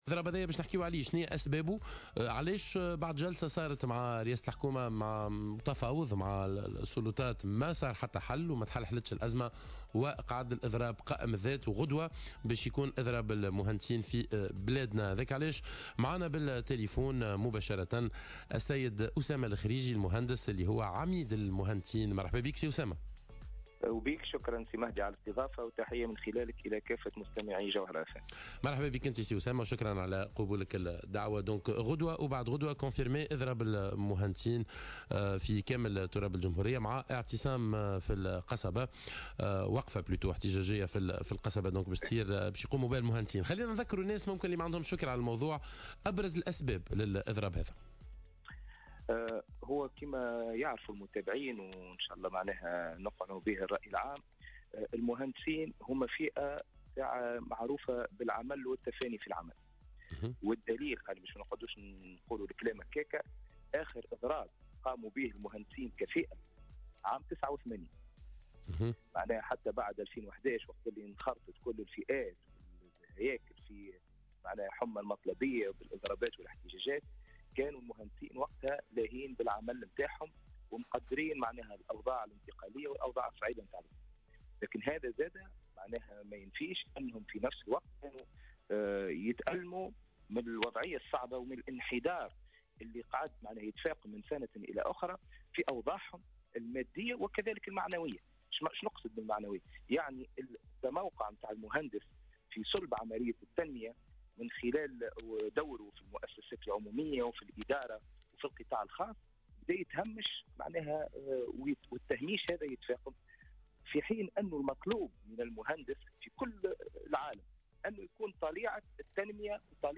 في مداخلة له في صباح الورد على الجوهرة "اف ام"